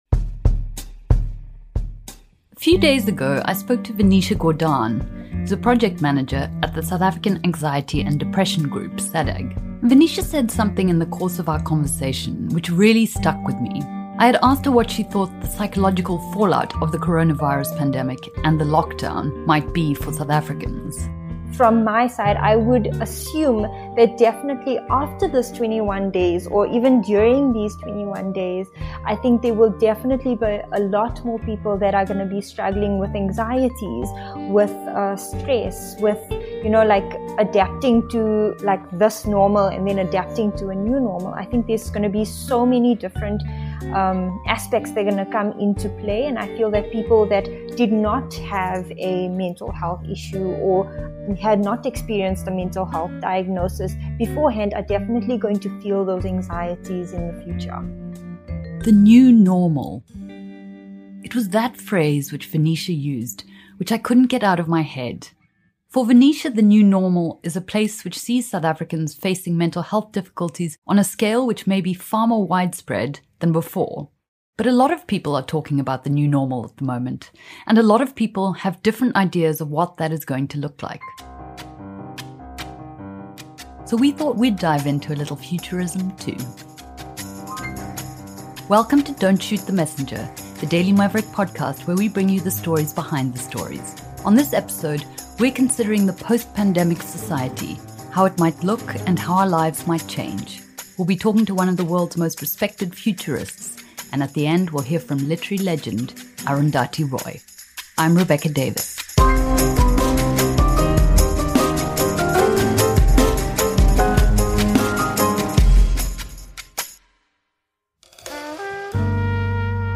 Ian Goldin, Professor of Globalisation and Development at the University of Oxford and author of The Butterfly Defect chats to us about the effects pandemics have on global co-operation, what technological advancements are being used and should be used.